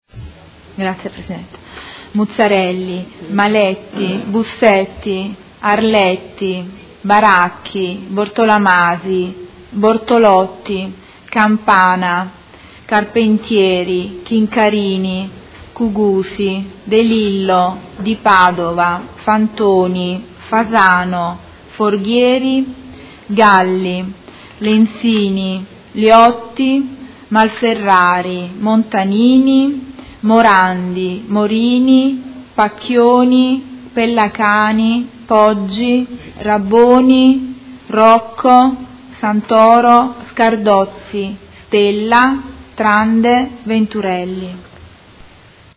Seduta del 27/10/2016 Appello